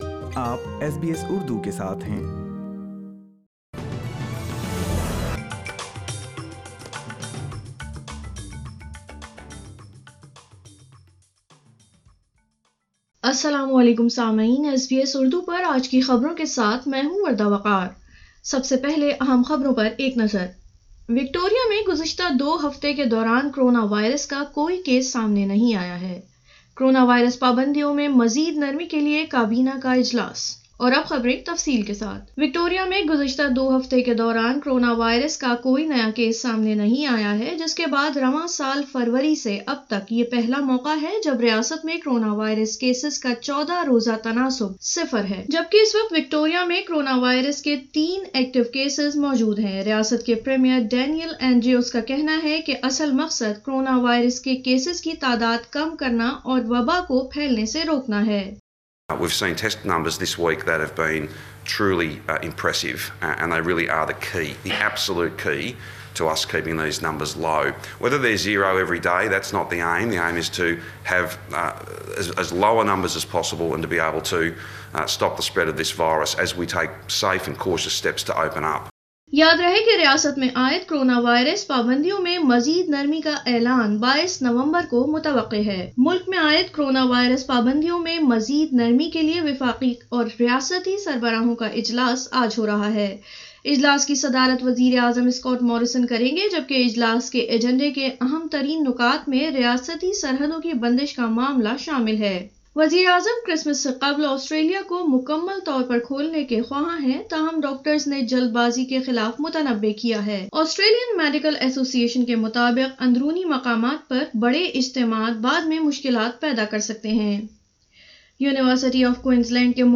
اردو خبریں 13 نومبر 2020